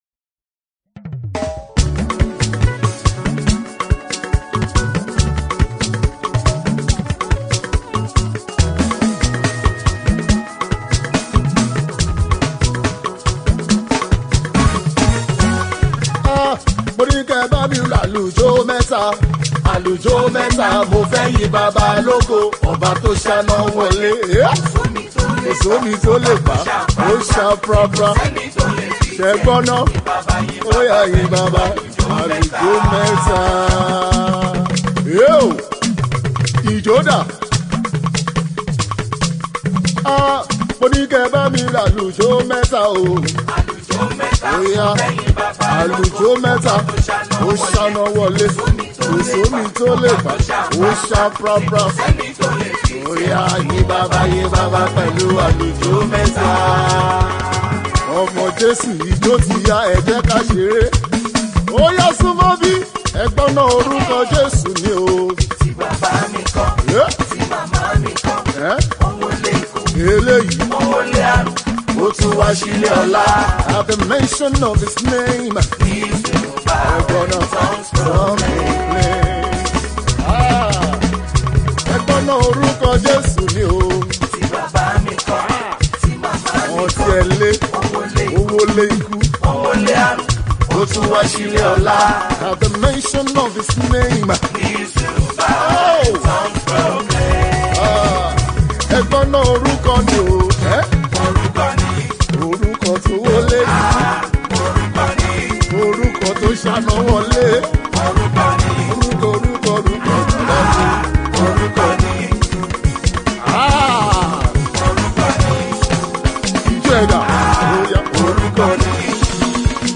one of Nigeria’s foremost Contemporary High-life Artiste
gospel music